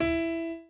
b_pianochord_v100l16-7o5e.ogg